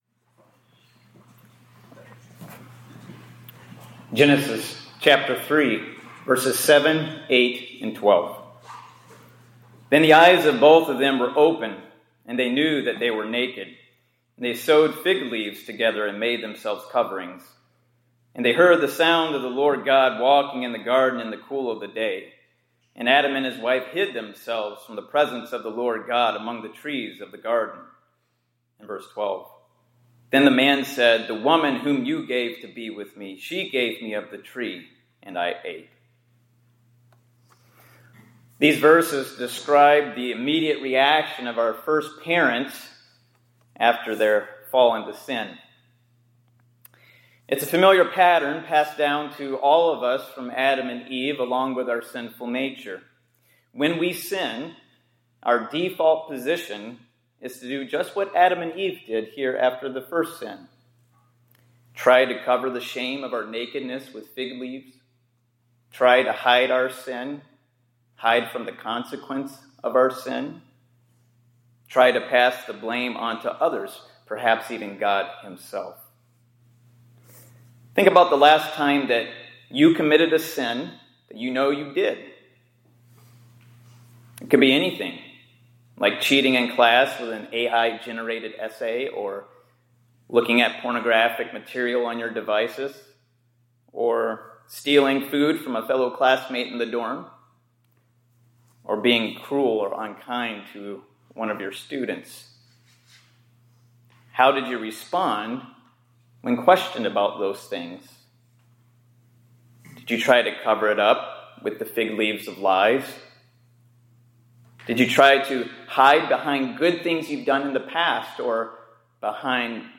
2026-02-06 ILC Chapel — Reversing the Default Reaction to Sin